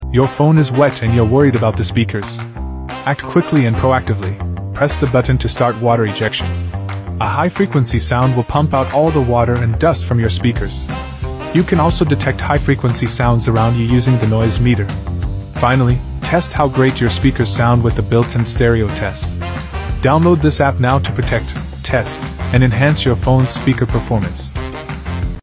High frequency waves ejects water from sound effects free download
High-frequency waves ejects water from your speakers.